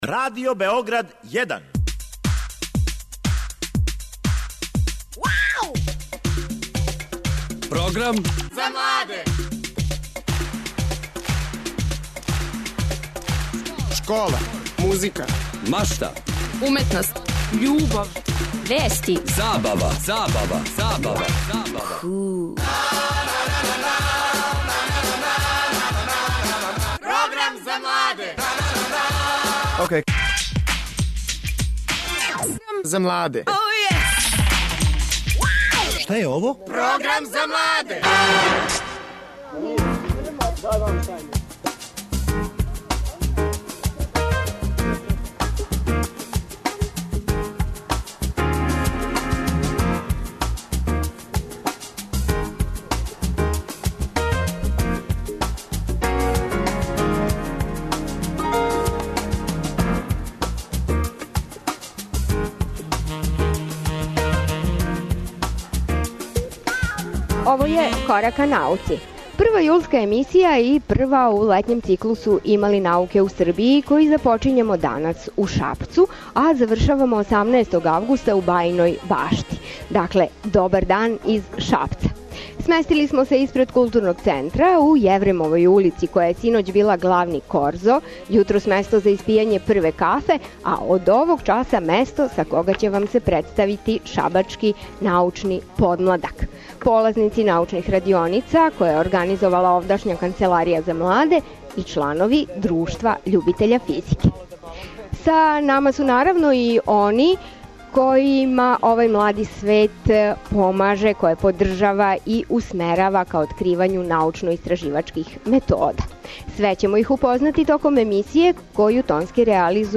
Емисија се реализује из Главне улице испред Културног центра Шабац.